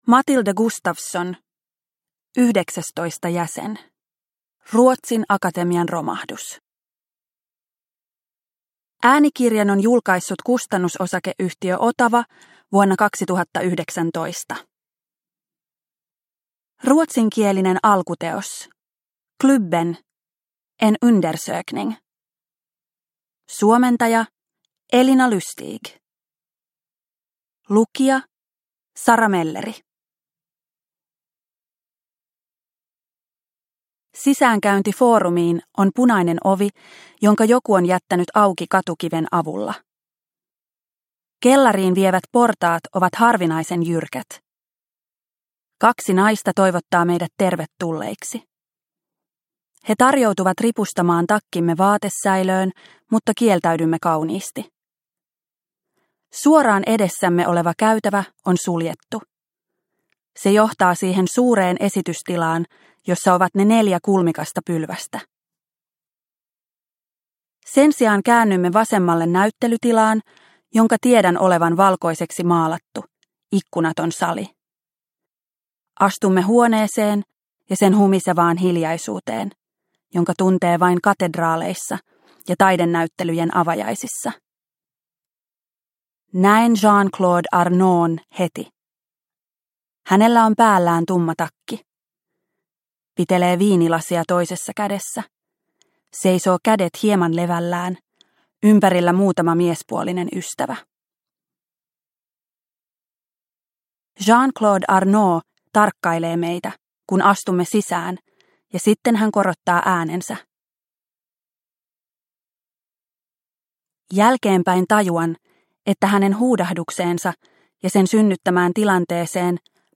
Yhdeksästoista jäsen – Ljudbok